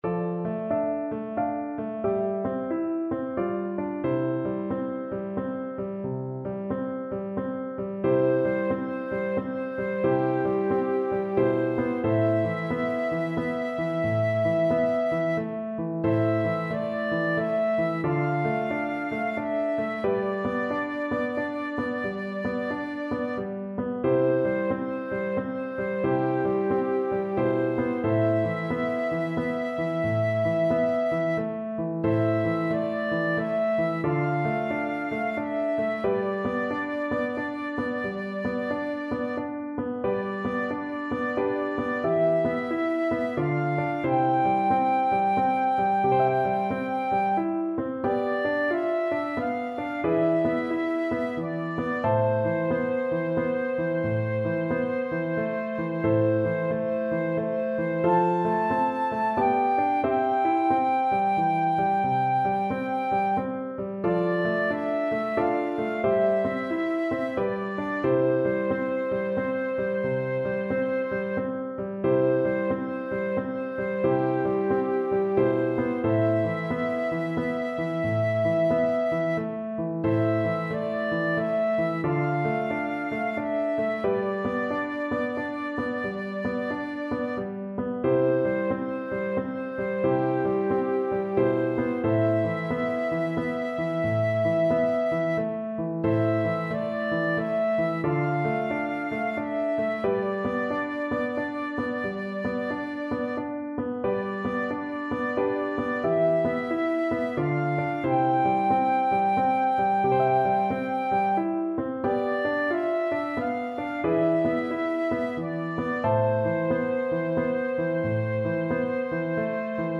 Flute version
Flute
C major (Sounding Pitch) (View more C major Music for Flute )
=90 Andante, gentle swing
3/4 (View more 3/4 Music)
Traditional (View more Traditional Flute Music)